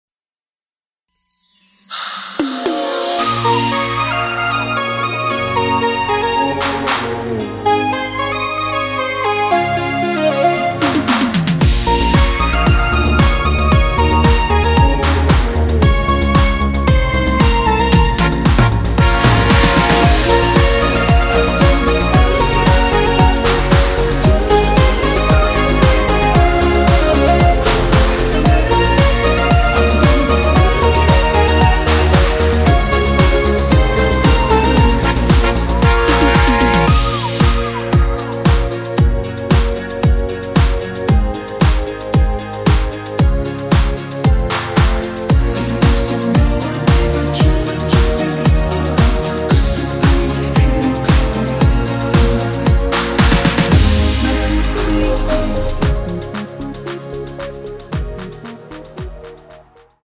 원키에서(-4)내린 코러스 포함된 MR입니다.(미리듣기 확인)
Bbm
앞부분30초, 뒷부분30초씩 편집해서 올려 드리고 있습니다.
중간에 음이 끈어지고 다시 나오는 이유는